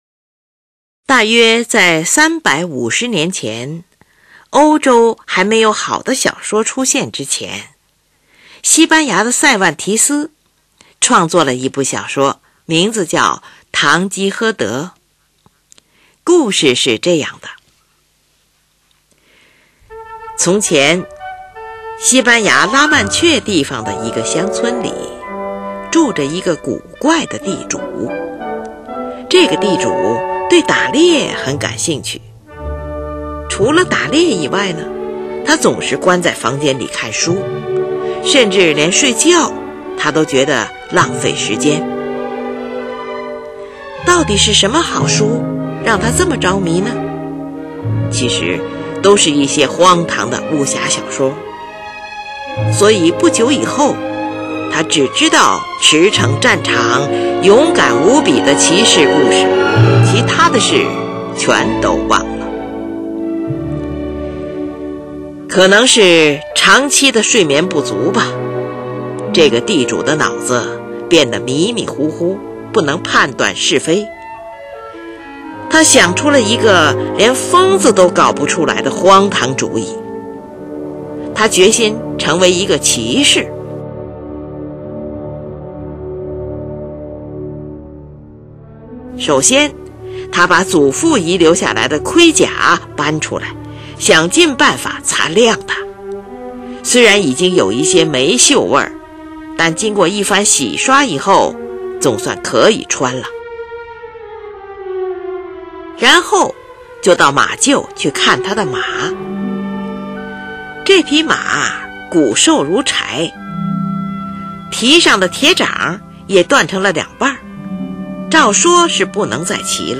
作品以大提琴来表示堂吉诃德的性格，全曲共包含序曲、10个变奏与终曲。
（序曲）作品先由长笛、双簧管暗示堂吉诃德的主题，其中含主题动机，表示中世纪骑士的性格。
（1）缓慢的独奏大提琴与低音单簧管对位表现堂吉诃德与桑丘的出发。
弦乐震音表现尘土飞扬，堂吉诃德错把羊群当作敌方大军，铜管奏羊的叫声，木管奏牧羊人的笛声。